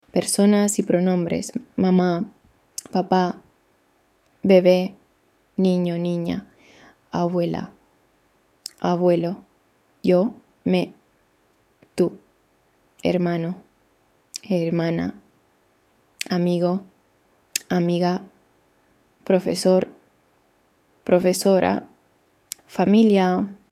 Lesson 1